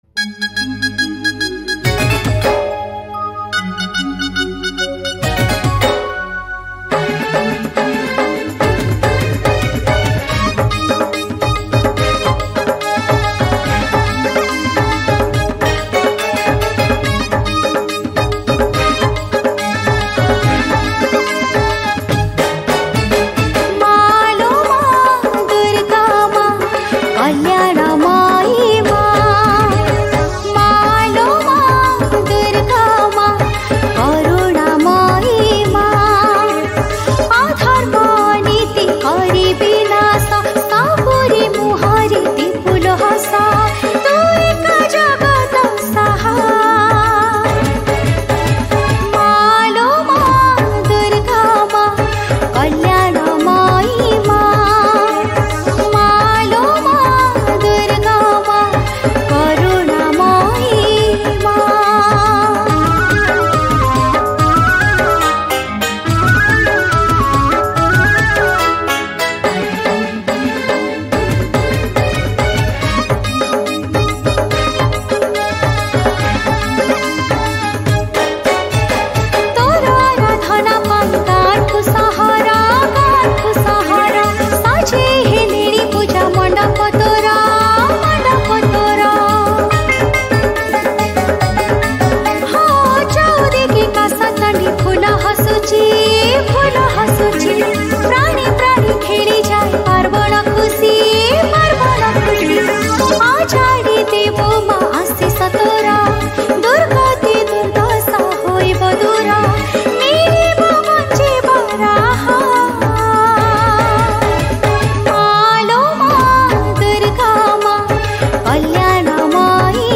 Odia Bhajan
Category: Odia Bhakti Hits Songs